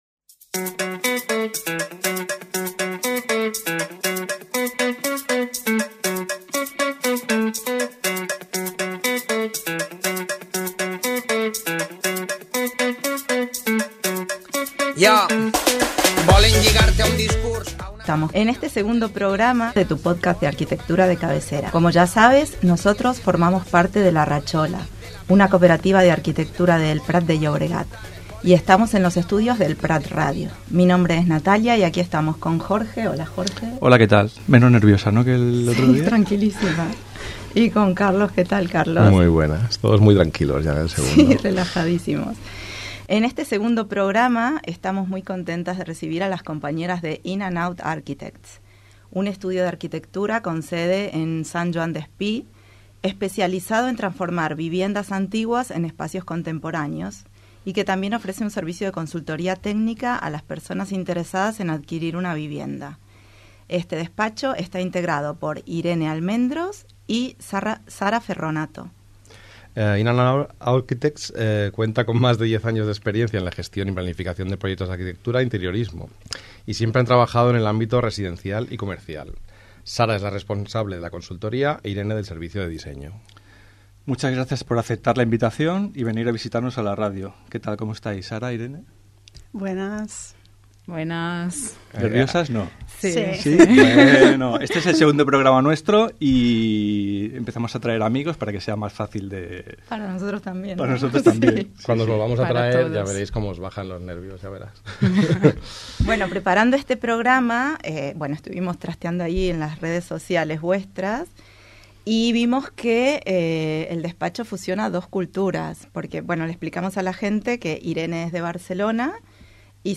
El magazín matinal d'elprat.ràdio. Un espai obert, participatiu i plural on conèixer en profunditat tot el que passa a la ciutat.